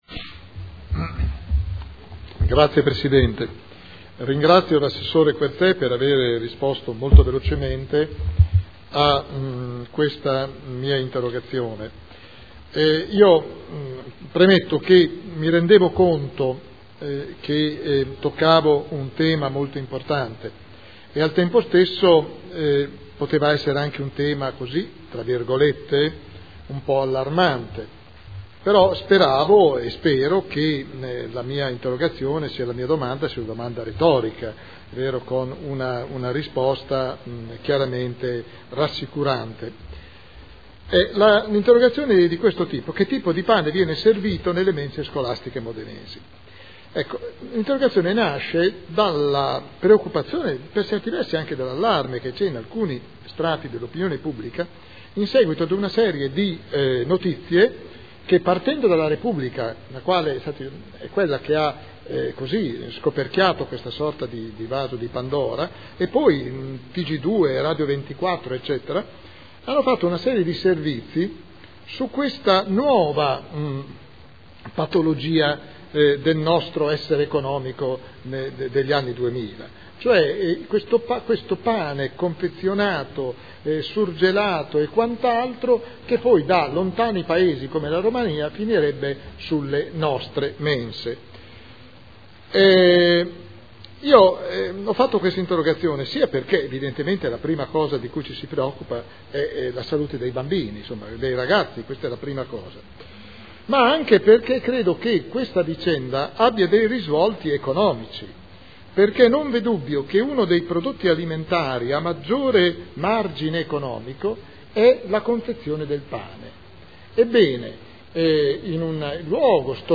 William Garagnani — Sito Audio Consiglio Comunale